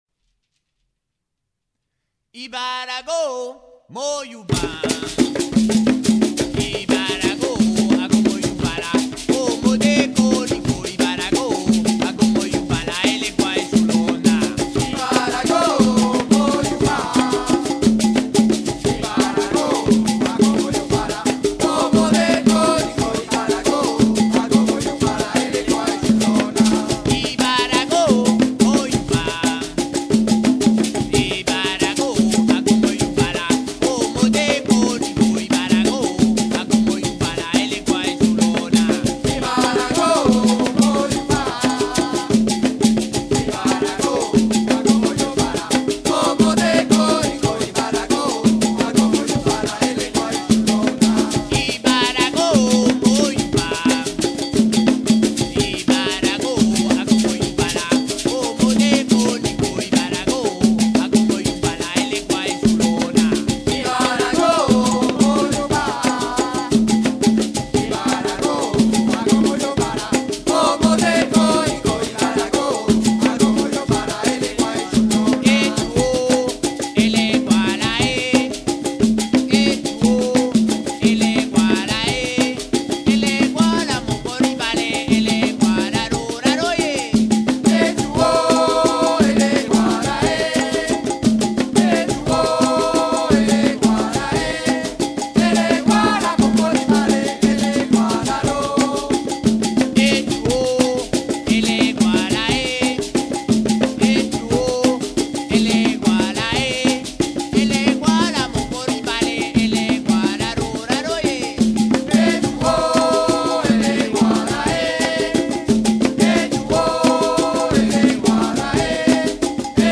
lead vocal and catchimbo
coro and first mula
coro and second mula
coro and caja (impro)
coro and guataca
coro and chékéré